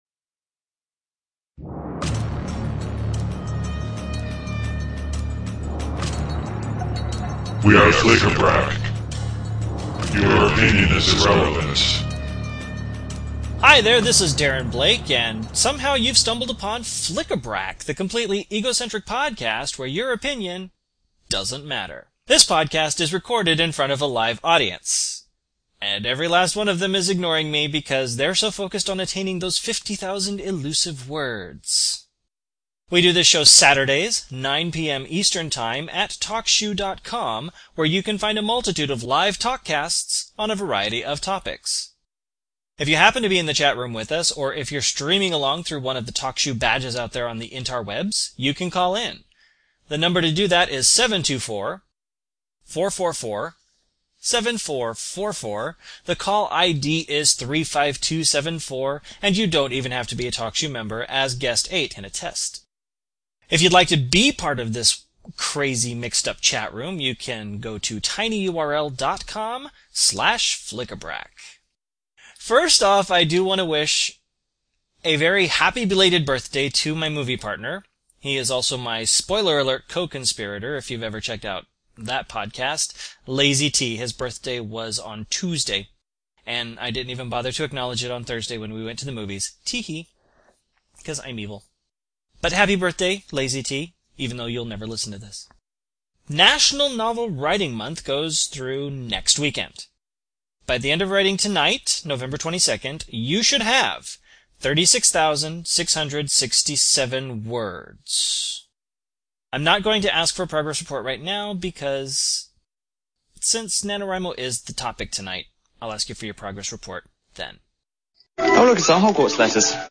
There were even a few excerpts from the writing.
Of course, with the good comes the not-that-great: Since this was my show, it’s not very well done.